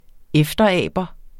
Udtale [ -ˌæˀbʌ ]